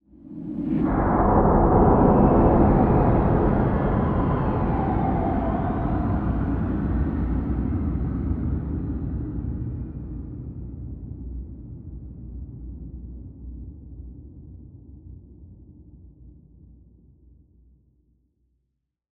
SnootGame/game/audio/effects/shooterFangJumps.ogg at bb971e75cc5ca3dd04e103a57c8188e2bb232a95
shooterFangJumps.ogg